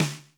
RX5 SNARE 2.wav